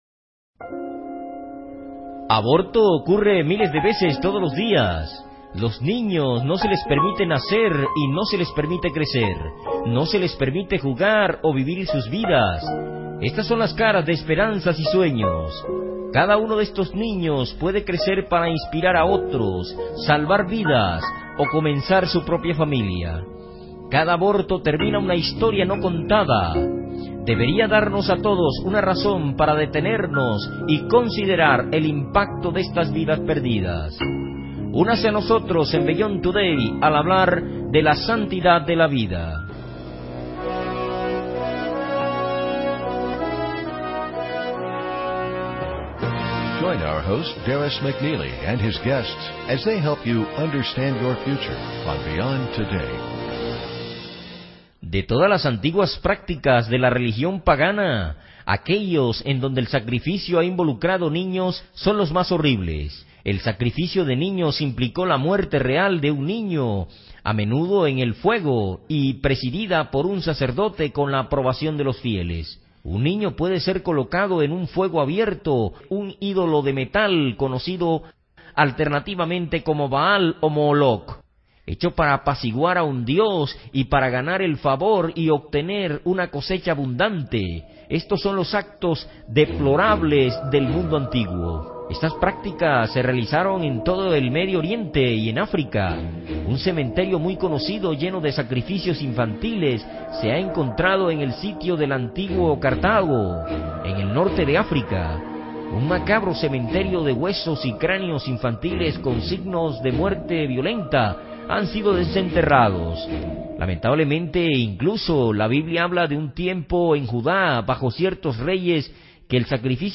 Beyond Today programa de televisión